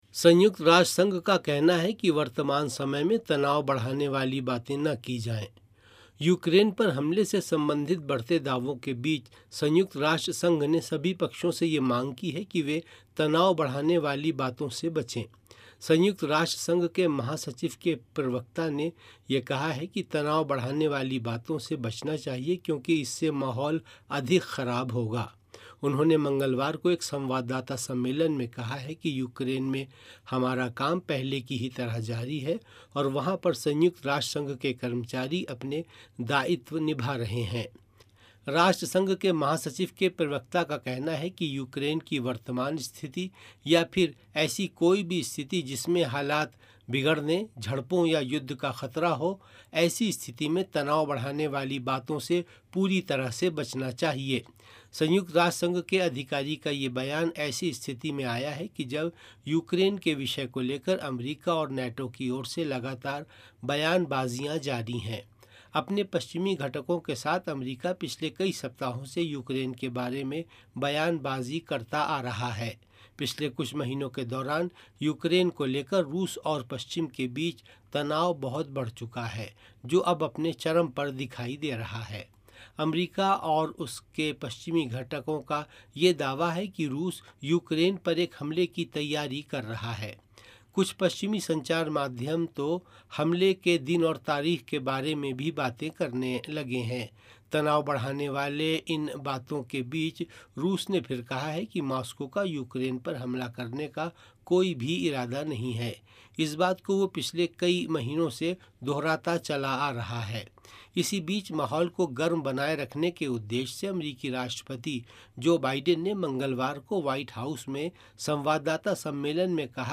राष्ट्रसंघ का अनुरोध, तनाव बढ़ाने वाली बातें न की जाएंः रिपोर्ट